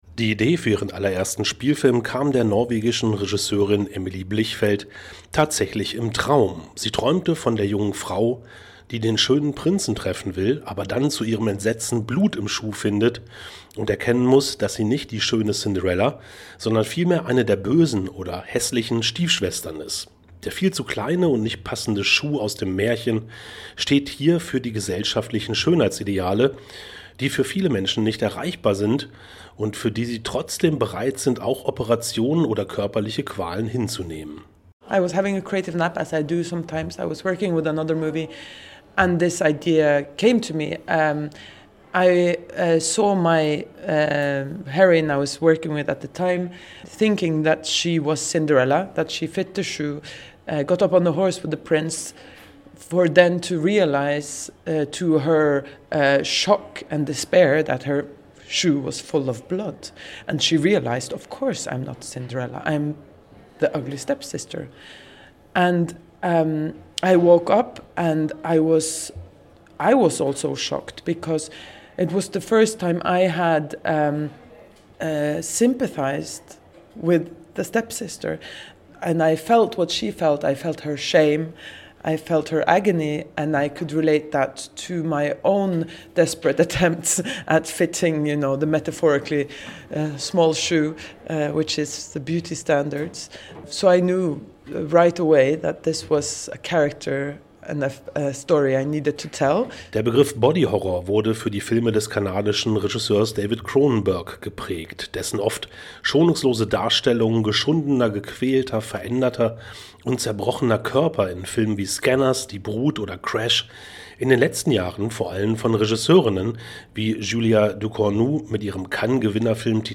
(Radobeitrag zu The Ugly Stepsister)
(Dieser Beitrag erschien zuerst als Radiobeitrag in der Sendung „Filmriss – Das Berlinale-Magazin“, einem gemeinsamen Projekt der norddeutschen Bürgersender Kiel FM, Lübeck FM, Westküste FM, Tide Hamburg, Radio Leinewelle und Oldenburg Eins.)